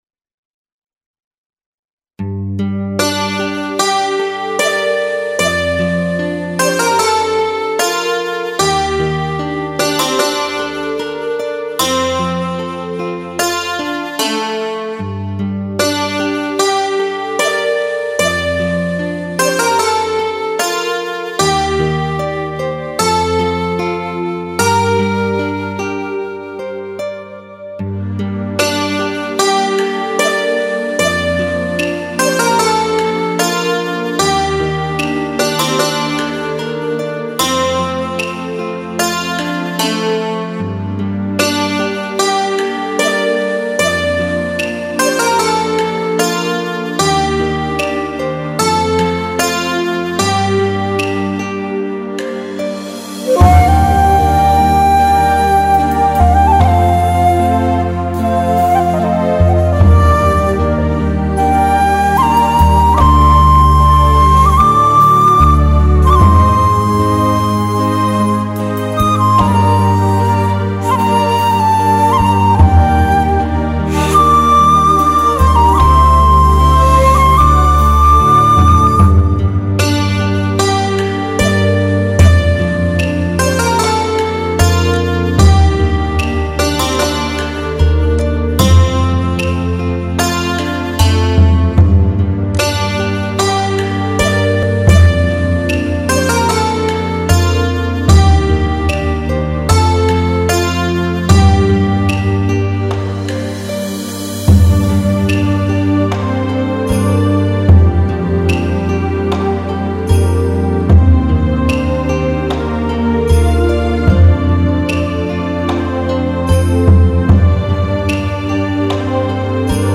很悲伤的一首音乐,从头到尾,都是伤感的。
既插曲，二年前就听过，不过他的音韵真的很好，很有一段日本民间的味道！